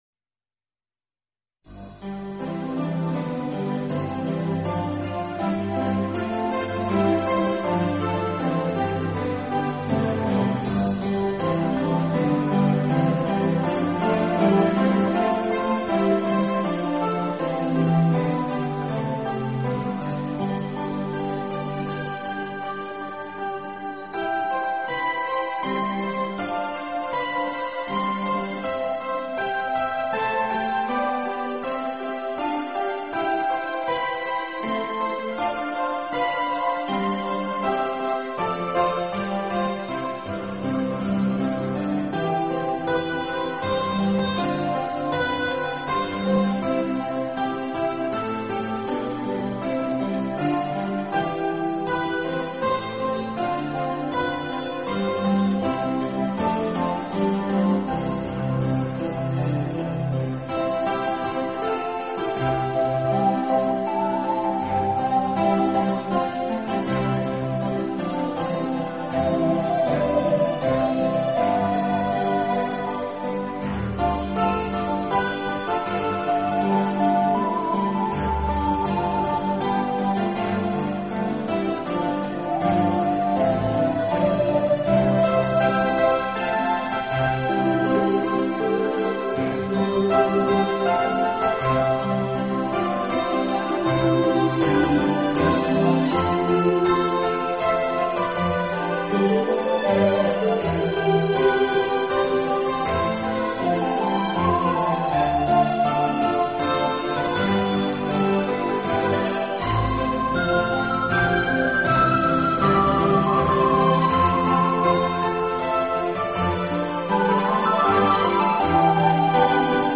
音乐类型: New Age